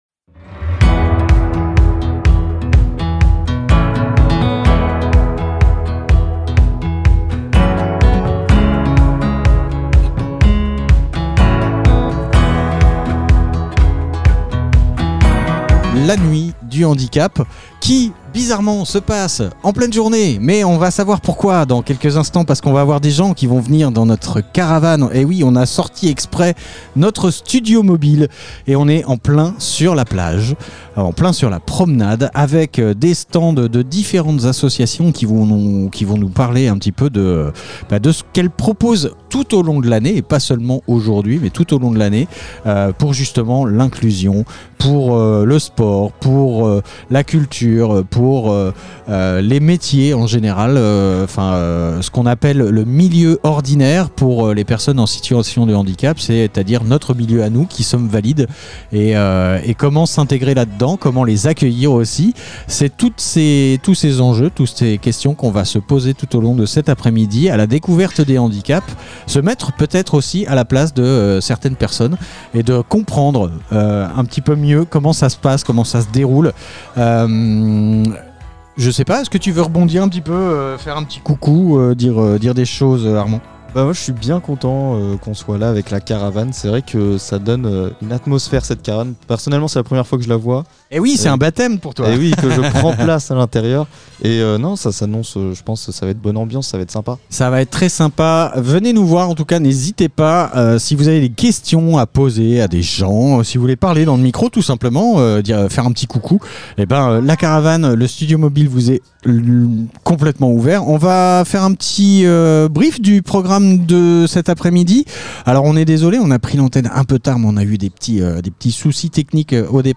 La nuit du handicap événement Interview handicap fécamp ville de fécamp nuit ville Nuit du Handicap